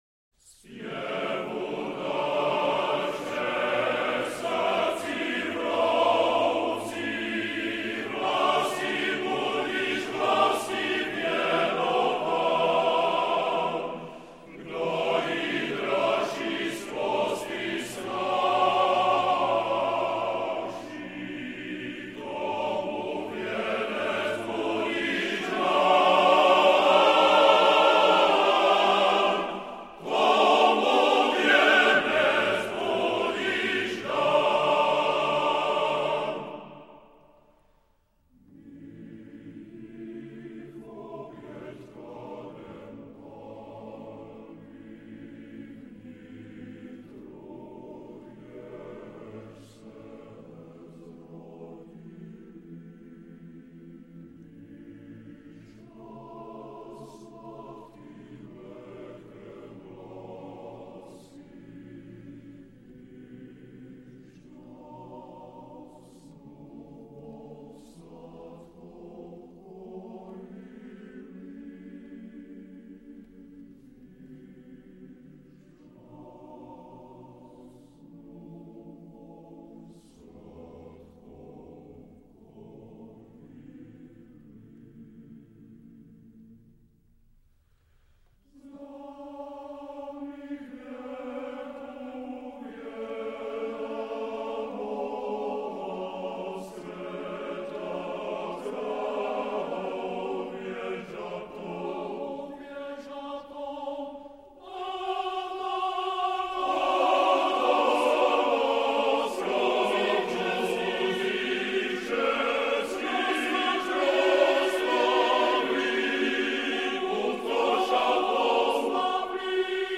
FF:VH_15b Collegium male choir